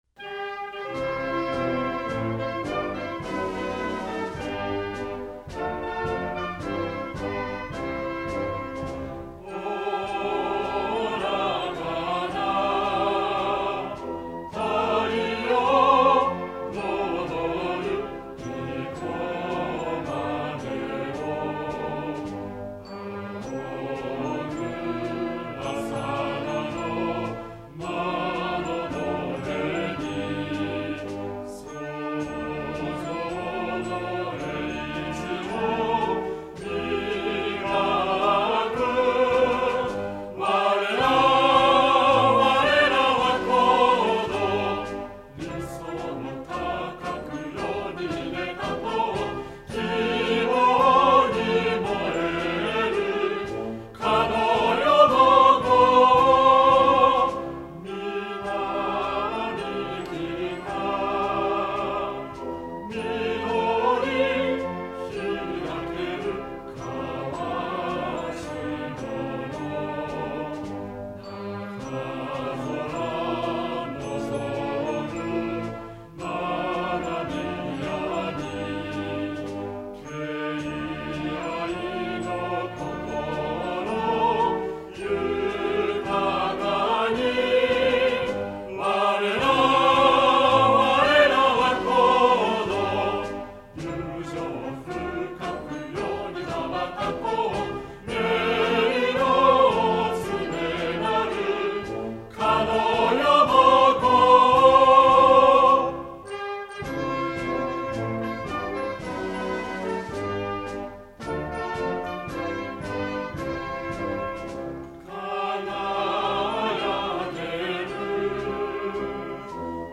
c_brass.mp3